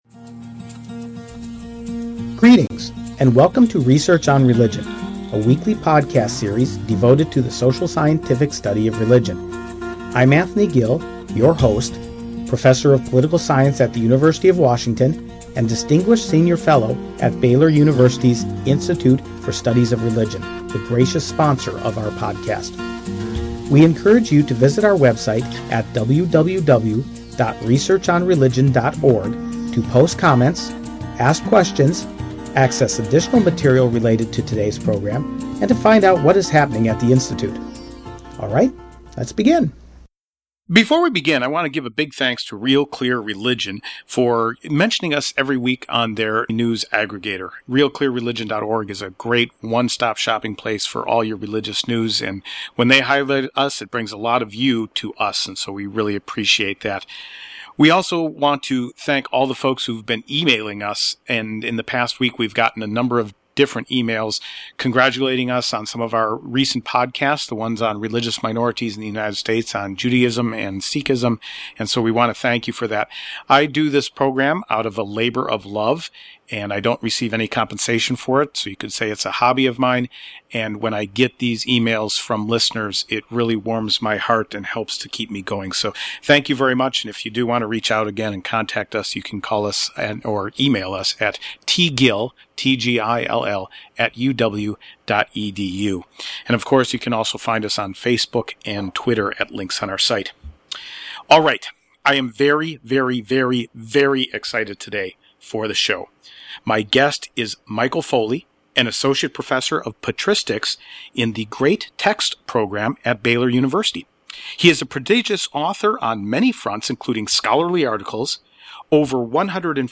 We cover a wide range of topics from the history of alcohol in the Bible to some brand new cocktail recipes. This interview is filled with fun facts, spiritual insights, and a wide variety of other surprises, a perfect pairing with whatever you might be drinking at the moment!